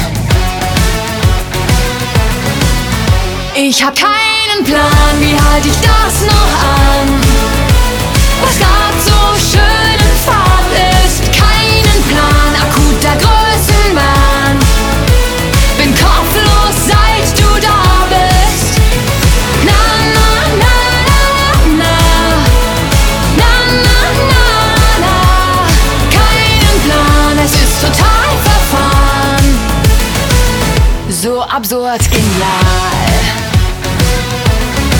Жанр: Поп / Русские
# German Pop